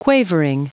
Prononciation du mot quavering en anglais (fichier audio)
Prononciation du mot : quavering